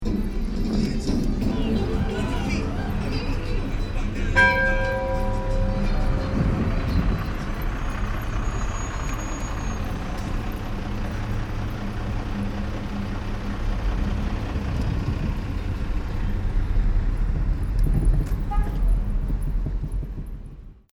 Day29.Ciudad Juarez.
one bell, one honk
We drove all over the place, ringing the bell as we drove the congested streets, adding to the already sound saturated air. Music blasting from speakers on the sidewalk, people walking around singing to themselves, cars, shouting- it felt very different than where we had come from.
juarez-1bell.mp3